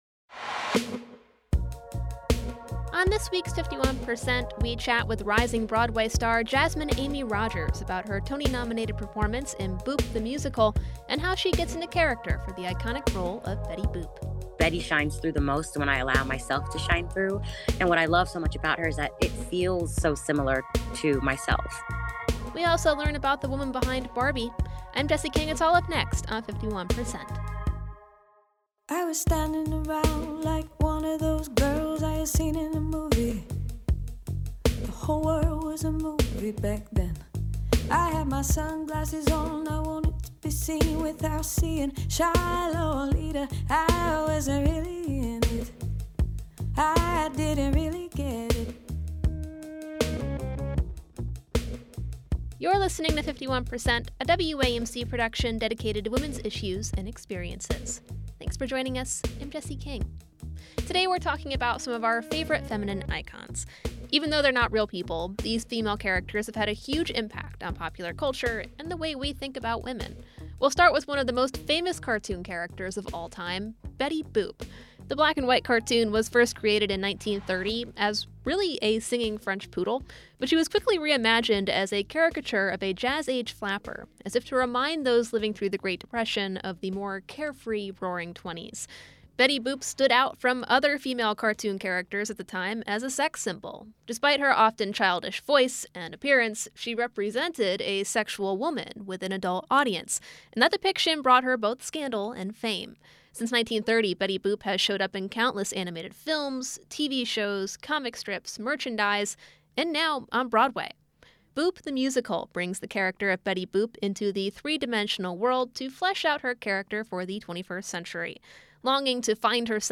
On this week’s 51%, we chat with rising Broadway star Jasmine Amy Rogers about her Tony-nominated performance in BOOP! The Musical, and how she gets in character for the iconic role of Betty Boop. We also stop by a lecture with the author of Barbie and Ruth: The Story of the World’s Most Famous Doll and the Woman Who Created Her. Guest: Jasmine Amy Rogers, actress and star of BOOP! The Musical on Broadway 51% is a national production of WAMC Northeast Public Radio in Albany, New York.